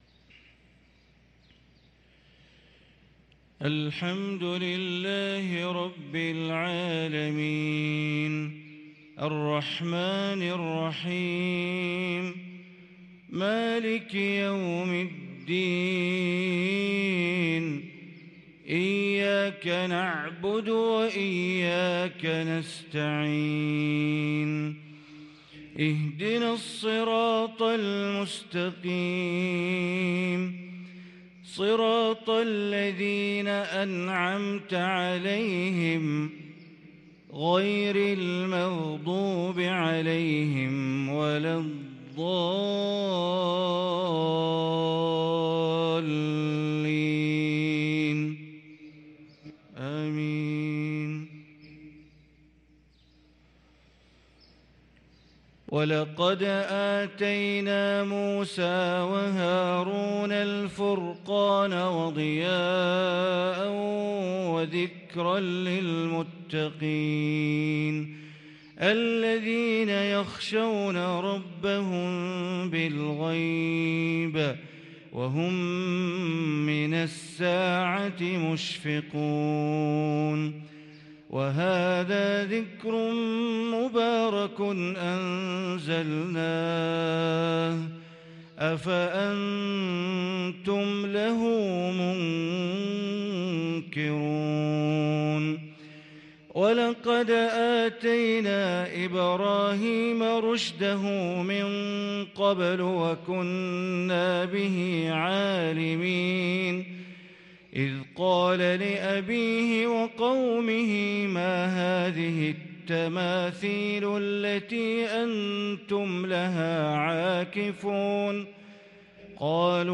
صلاة الفجر للقارئ بندر بليلة 11 صفر 1444 هـ
تِلَاوَات الْحَرَمَيْن .